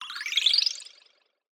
SFX_Magic_Healing_02_fast.wav